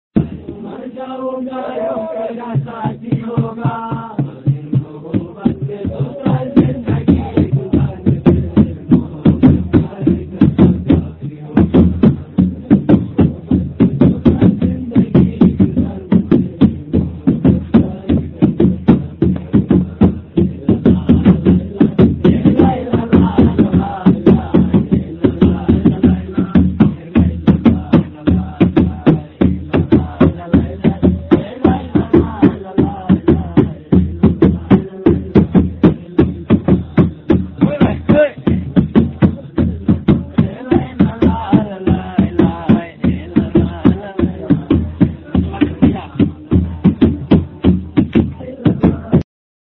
Oggi giornata di riposo qui a Paiju, e questa sera c’è anche una festa, con canti e balli, perché inizia il ghiacciaio vero e proprio e 11 dei nostri 120 portatori tornano a casa.
L'audio lascia alquanto a desiderare, ma più di questo non so fare...
musica della festa (mp3; 146kb)
musica_paju2.mp3